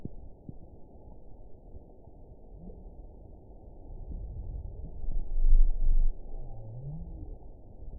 event 917971 date 04/24/23 time 15:14:59 GMT (2 years ago) score 7.96 location TSS-AB05 detected by nrw target species NRW annotations +NRW Spectrogram: Frequency (kHz) vs. Time (s) audio not available .wav